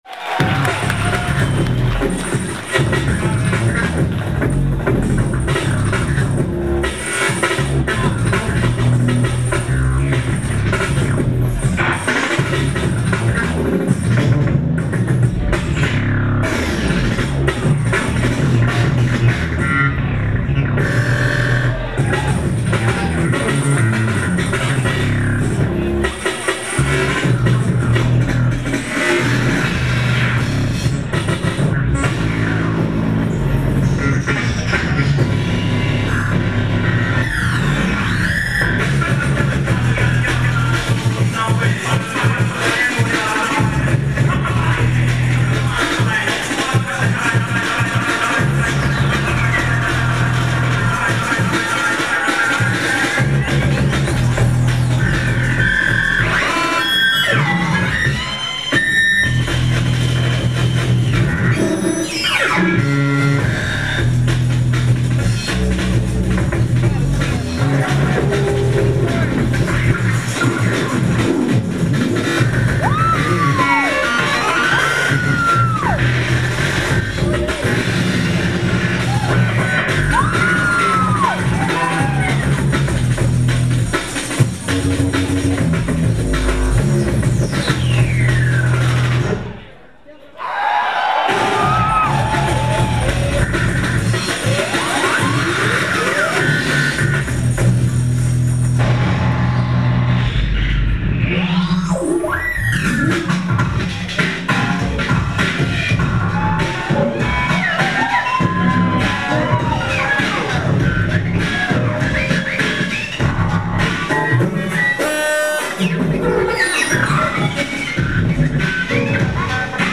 location San Francisco, USA